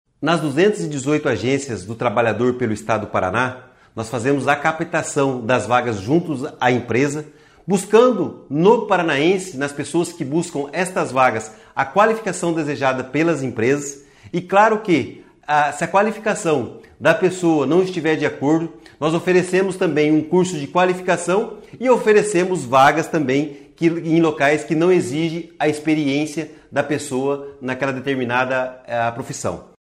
Sonora do secretário Estadual do Trabalho, Qualificação e Renda, Do Carmo, sobre a liderança do Estado na geração de empregos pela rede Sine